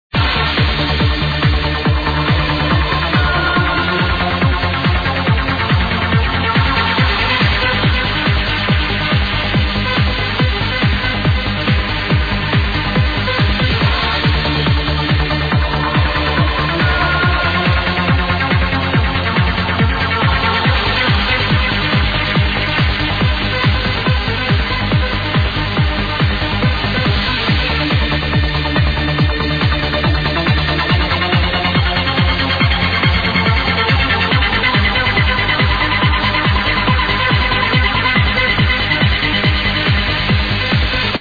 massive uplifter